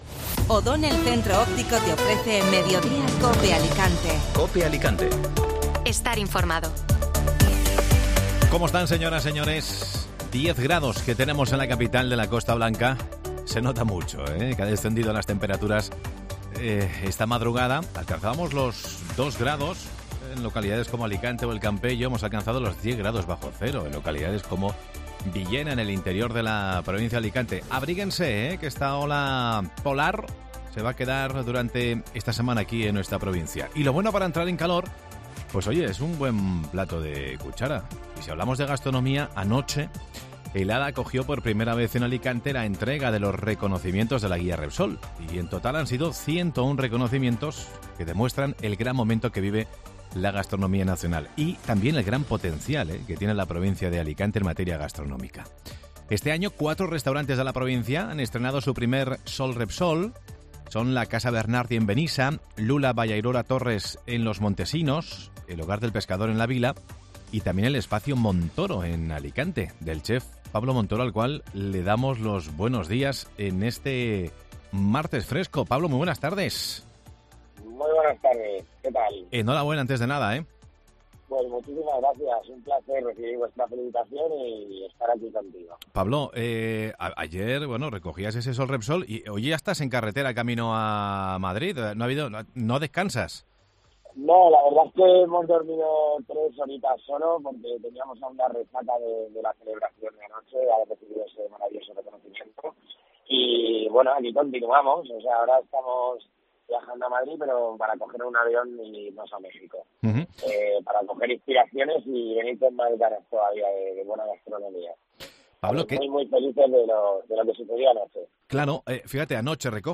Este martes ha pasado por los micrófonos de Mediodía COPE Alicante